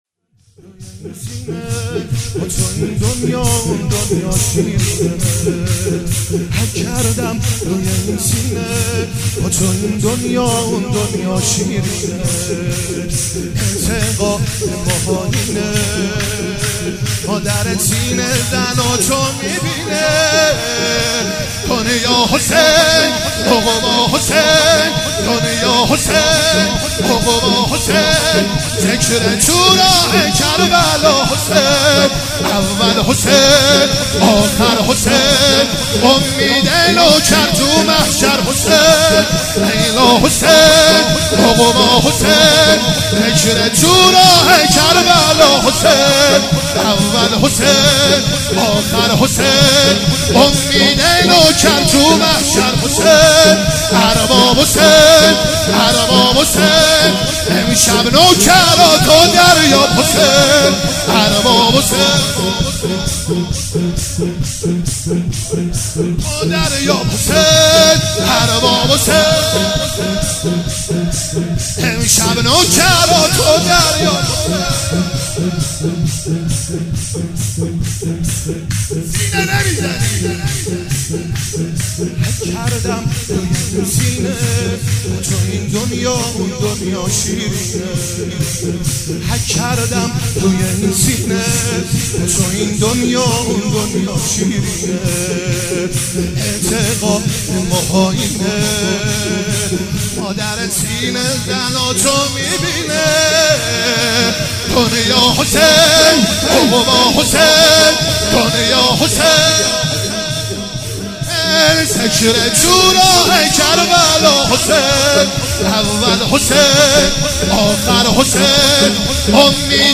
مناسبت : دهه دوم صفر
قالب : شور